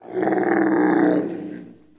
长颈鹿 | 健康成长
giraffe-sound.mp3